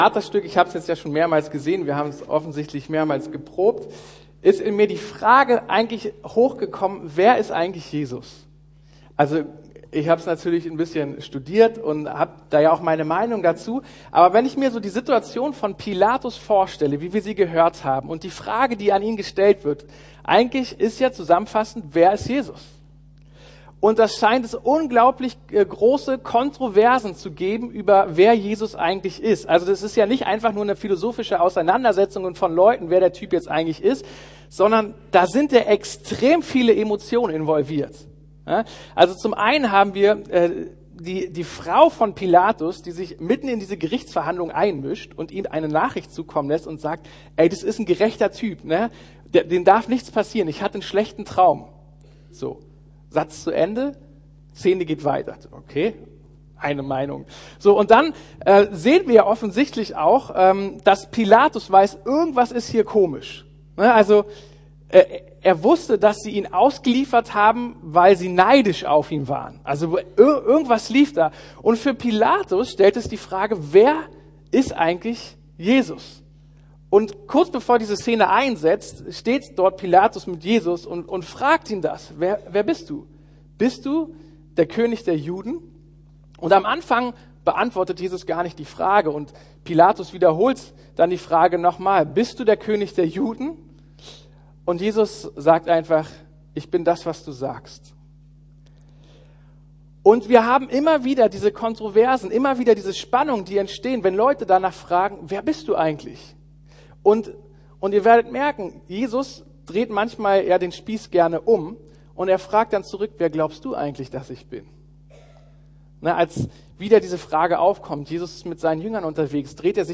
Jesus, Sohn Gottes?! ~ Predigten der LUKAS GEMEINDE Podcast